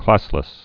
(klăslĭs)